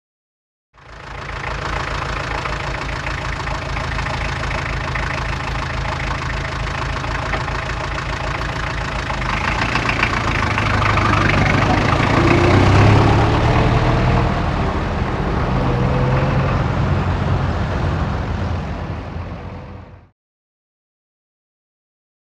Automobile; Idle / Away; Alfa Romeo Up And Stop.